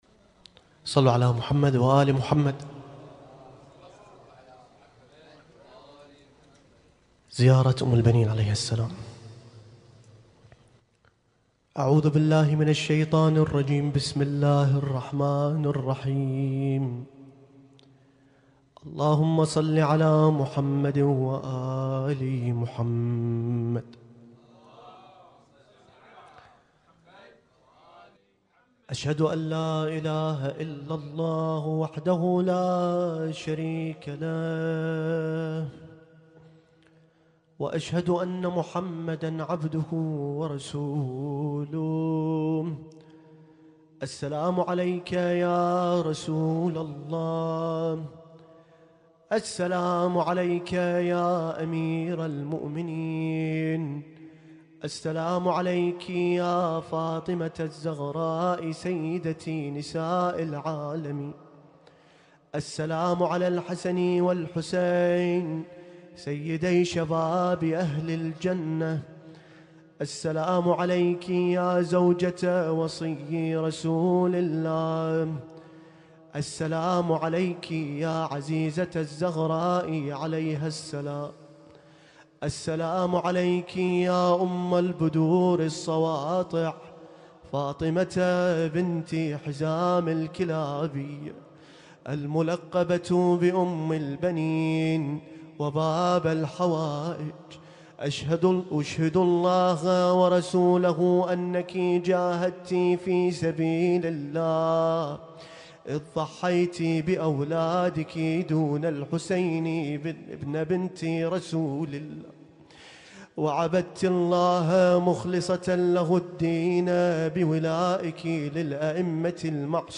القارئ: - الرادود